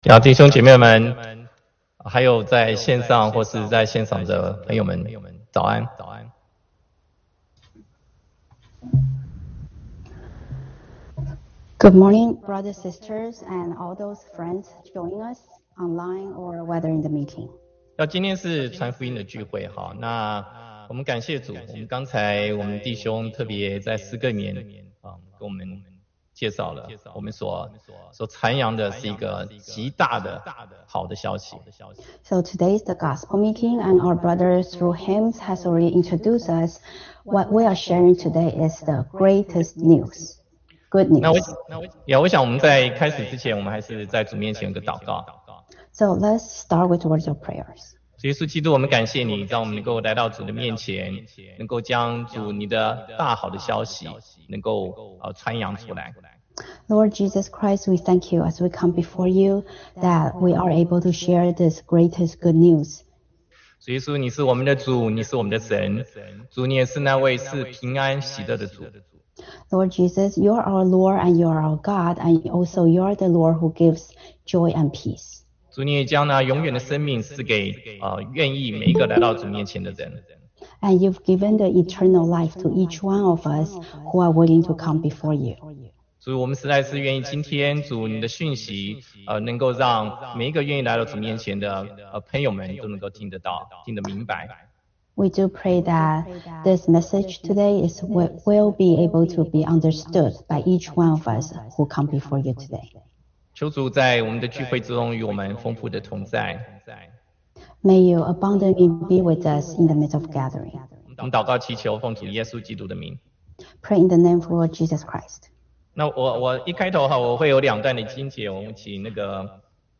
福音聚会 – 纽瓦克基督徒聚会